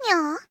boop.ogg